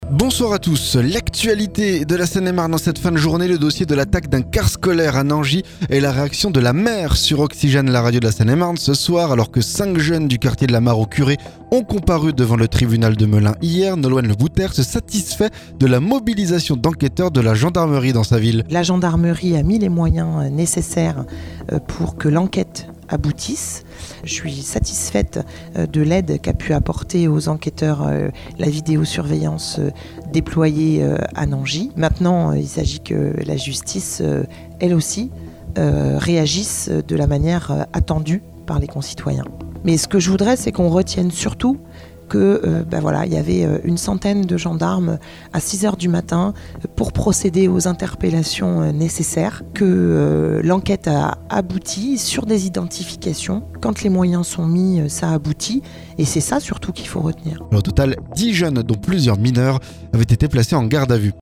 NANGIS - La maire réagit suite aux arrestations liées au caillassage d'un bus